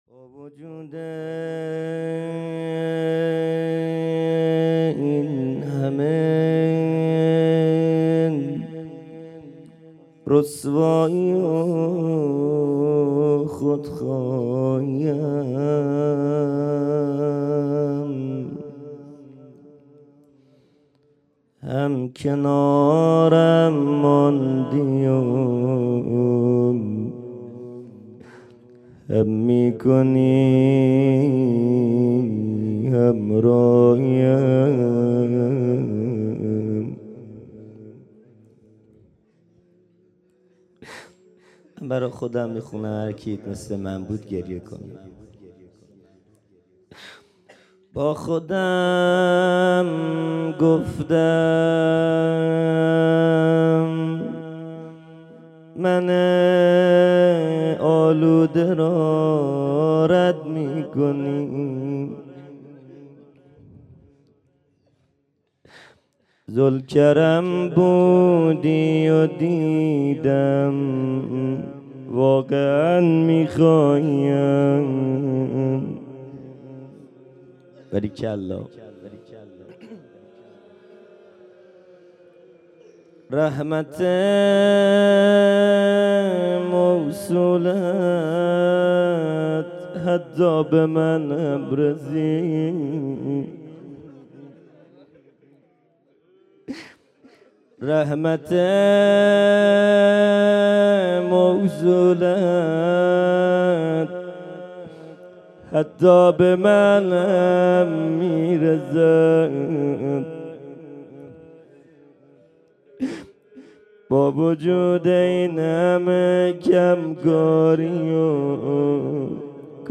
خیمه گاه - هیئت بچه های فاطمه (س) - روضه | با وجود این همه رسوایی و خود خواهی ام
جلسه هفتگی 2 اسفند 1397 ( وفات حضرت ام البنین(س) )